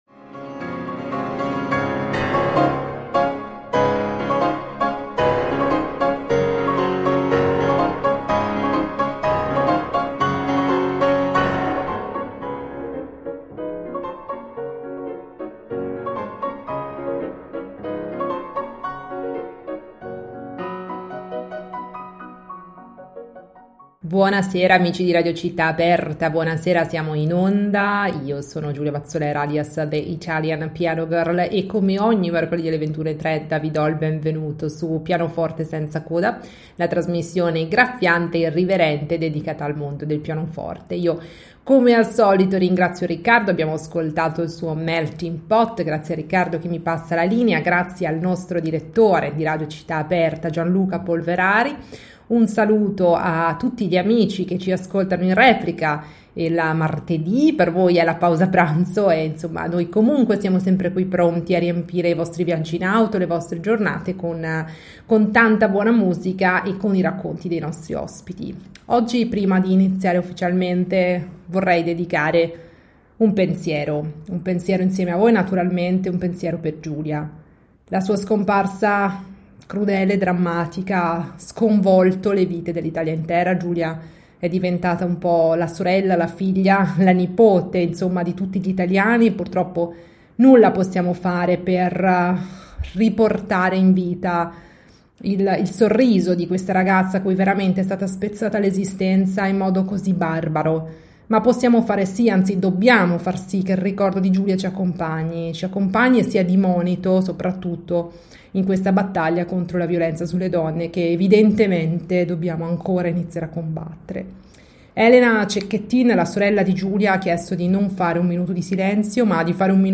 Da Foggia con furore.